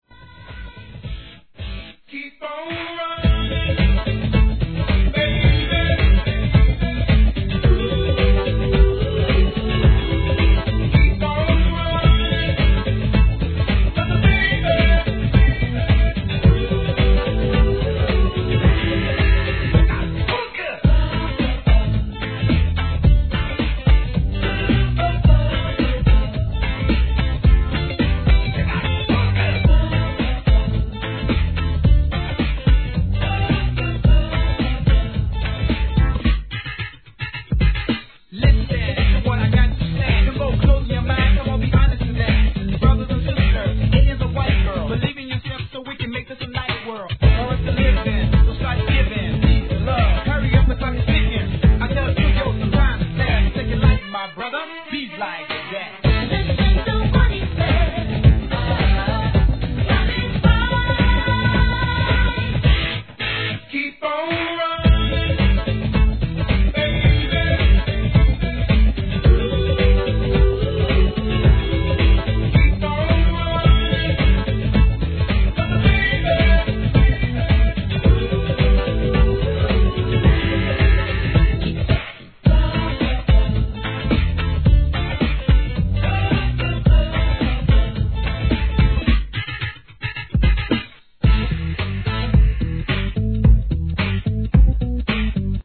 途中REGGAEのリズムに挿し変わったりとなかなか面白いです♪ No. タイトル アーティスト 試聴 1.